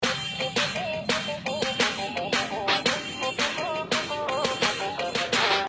piri-sample.mp3